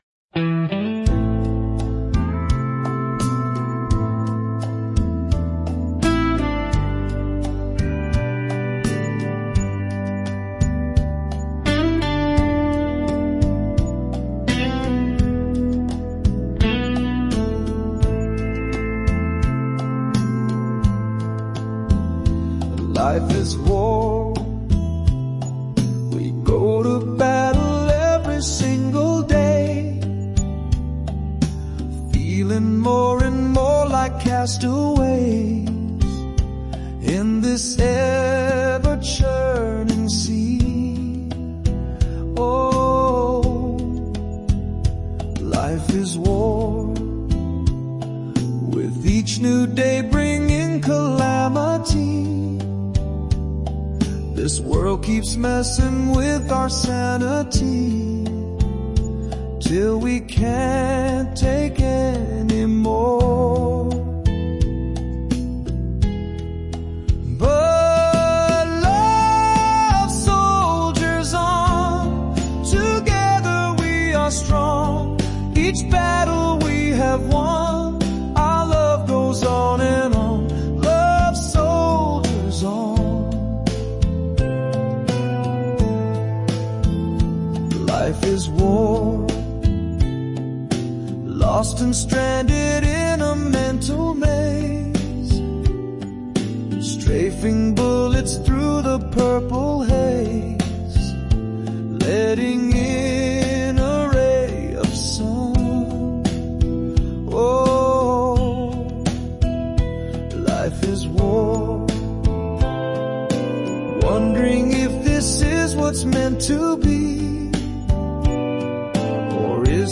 Music and vocals are embedded, to liusten just press play!